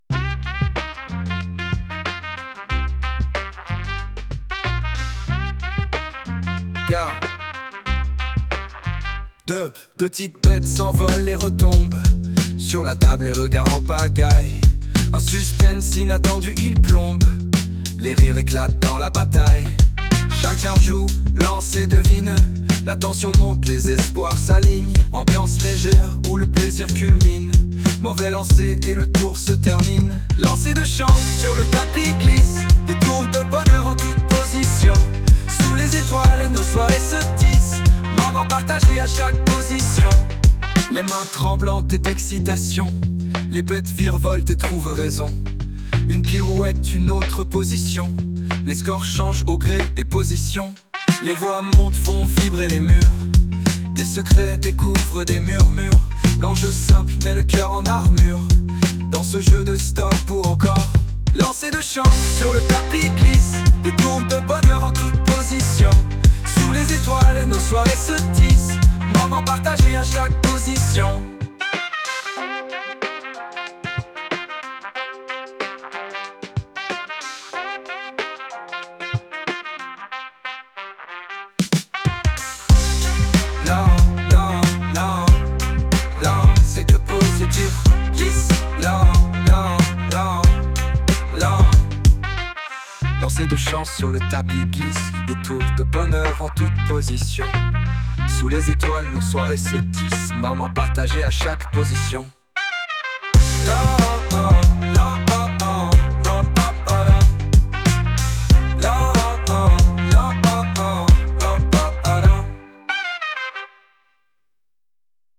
�Continuons avec du classique : musique jeu 63